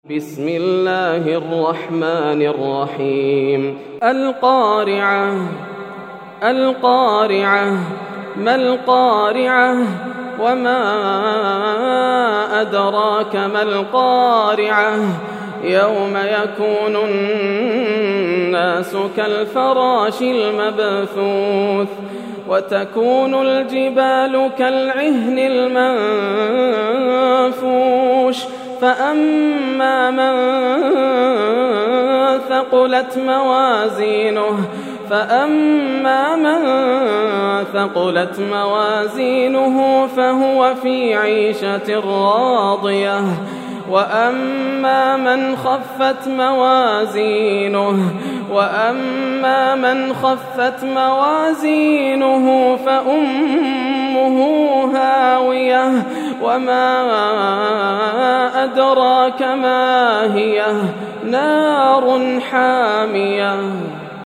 سورة القارعة > السور المكتملة > رمضان 1431هـ > التراويح - تلاوات ياسر الدوسري